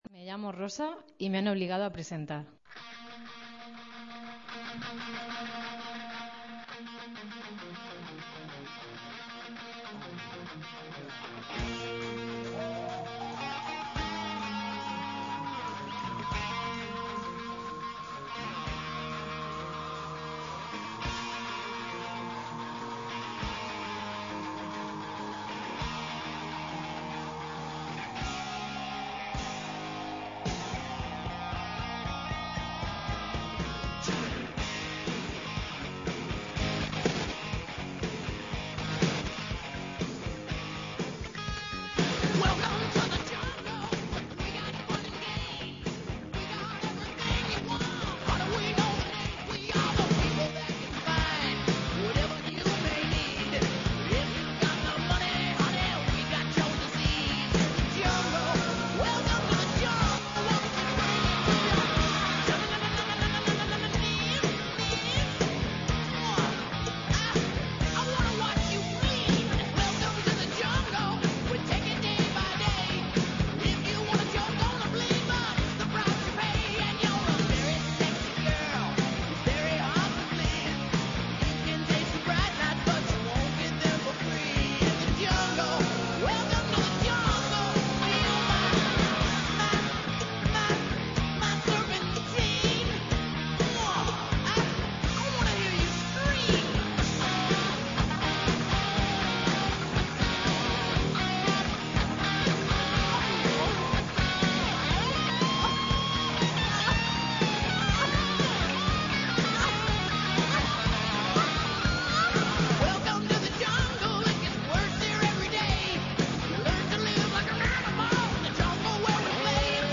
Así que no se lo tendremos muy en cuenta, que además, después de la faena inicial, se puede decir que hasta se portó bien y no nos causó apenas problemas técnicos.